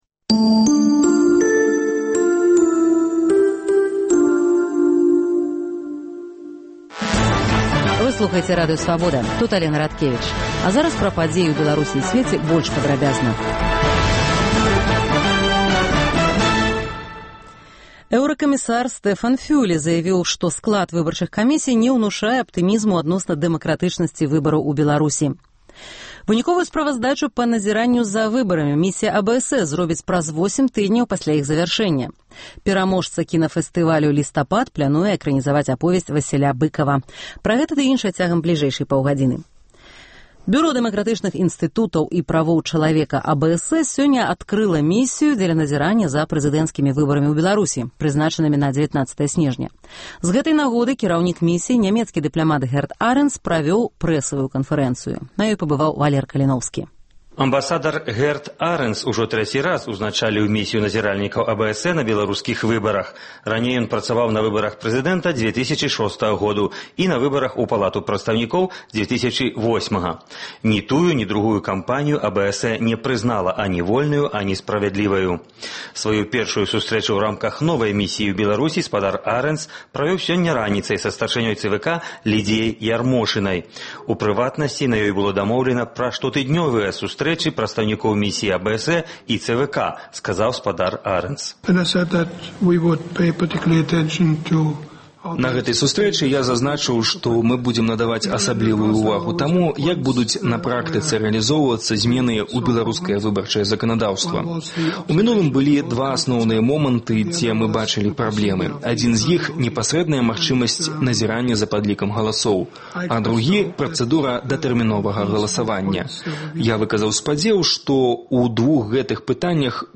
Паведамленьні нашых карэспандэнтаў, званкі слухачоў, апытаньні ў гарадах і мястэчках Беларусі